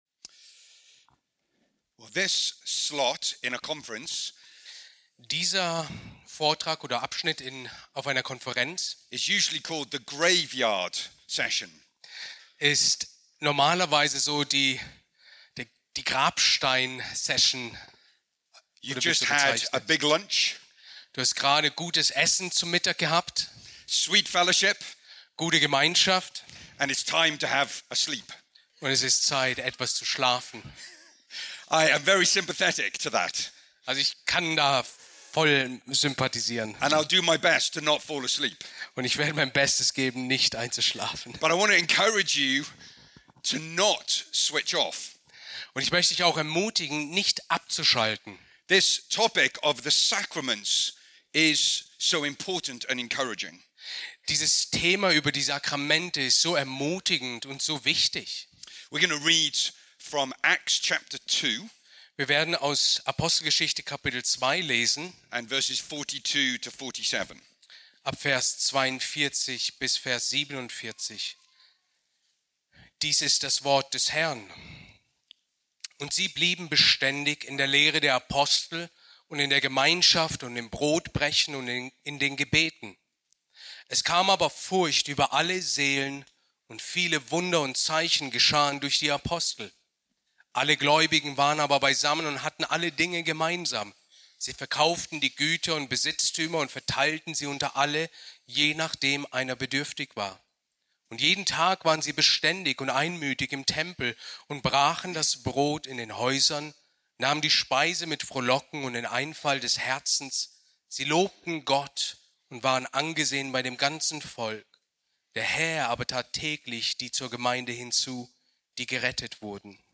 Predigt aus der Serie: "ERG-Konferenz 2026"